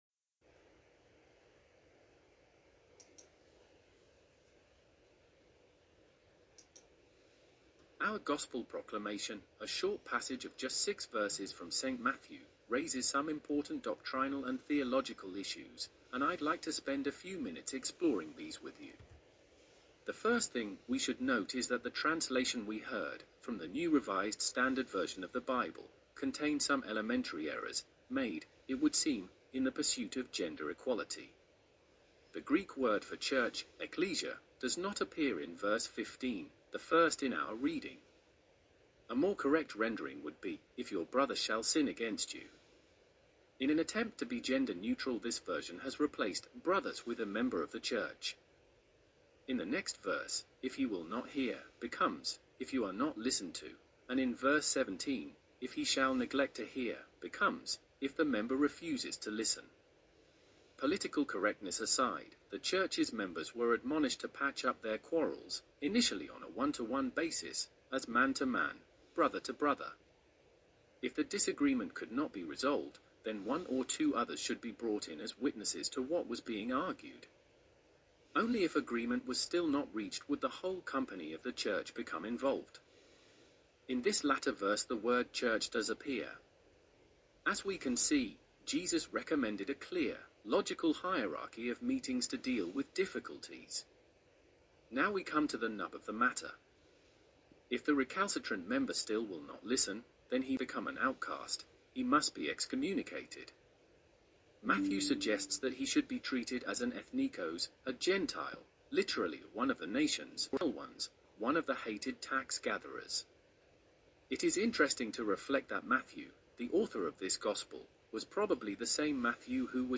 Trinity XI - 4th September 2011 - Sermon